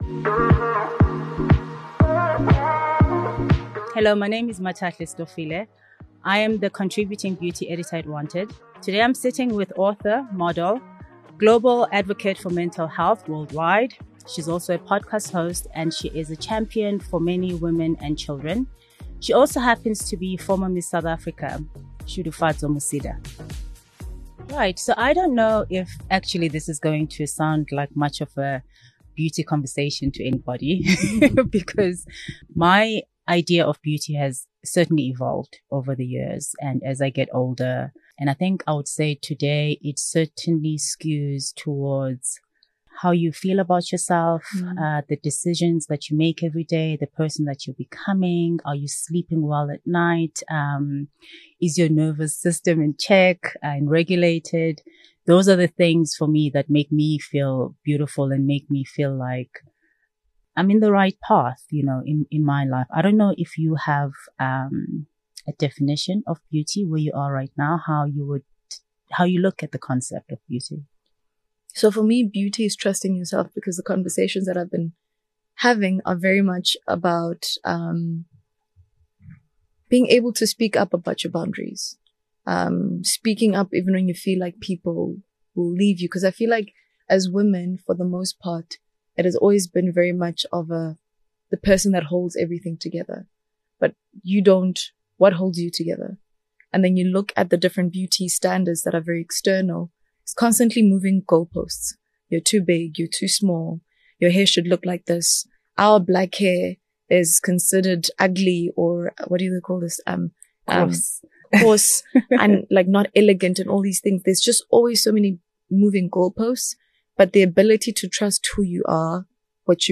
In this episode of the Wanted Podcast, we bring you a conversation about beauty through the lens of identity, spirituality, and a calm, regulated nervous system as the ultimate beauty hack.